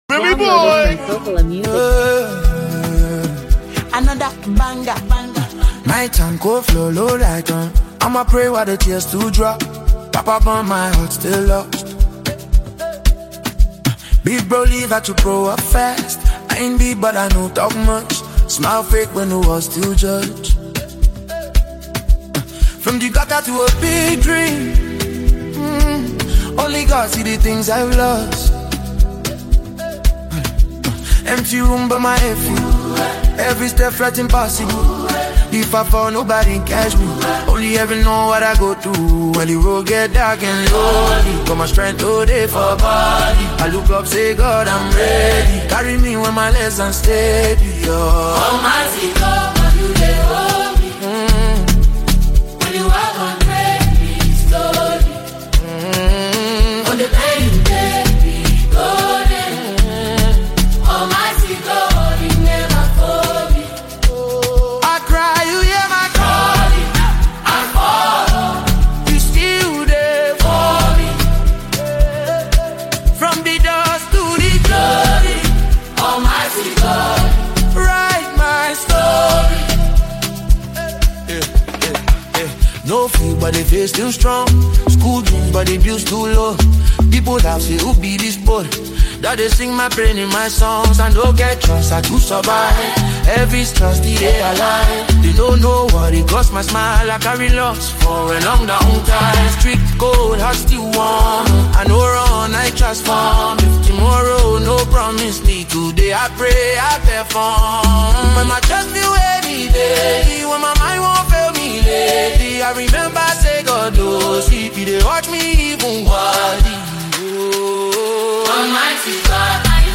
spiritually reflective and emotionally powerful song